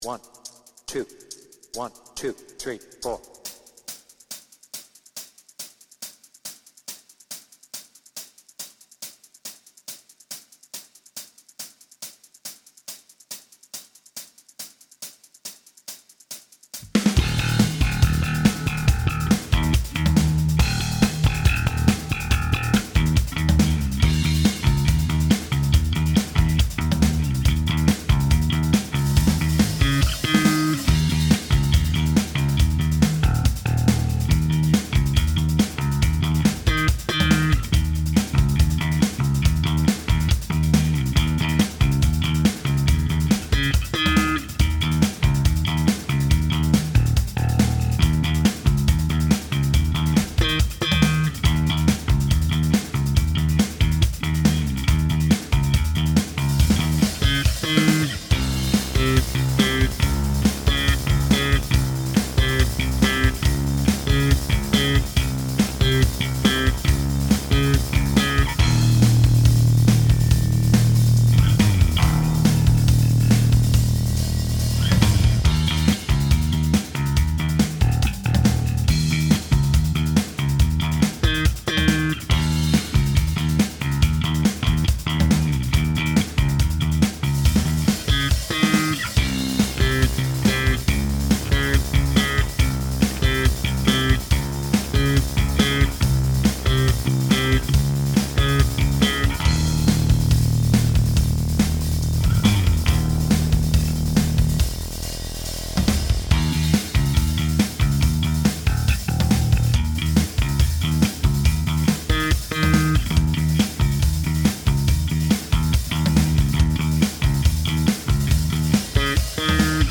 BPM : 140
Tuning : Eb
Without vocals